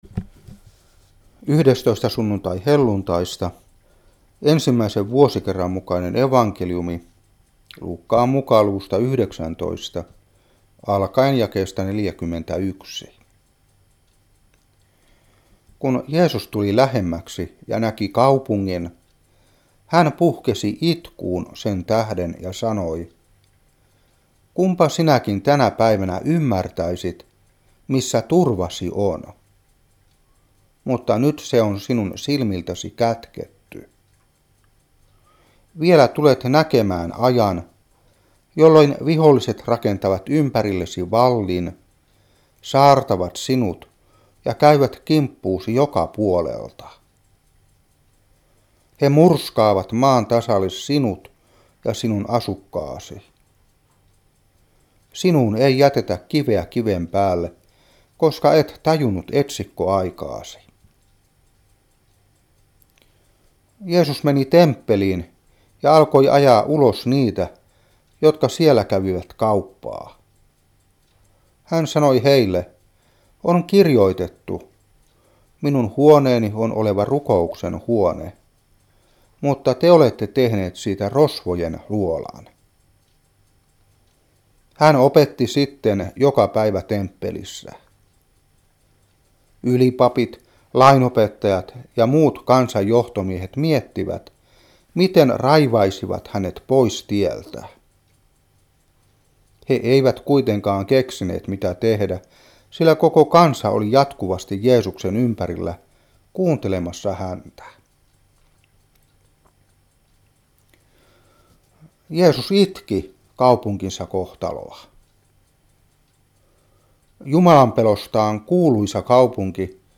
Saarna 2010-8.